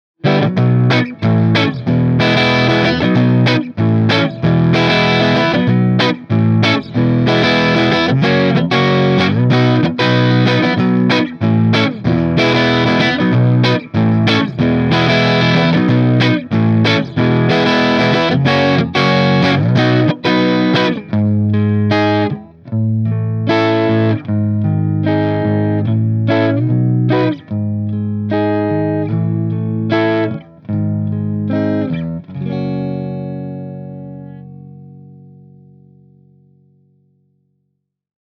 Ch.1 Clean was as follows - no MV, cut 3:00, vol 7:30, contour pos 2 (from left), munch/hi on the back, Lo input on the front.
Definitely different flavours... the Trinity cab is so much more detailed while the Orange is significantly deeper sounding...
TC15_Ch1_Clean_SD59_Neck_ORANGEcab.mp3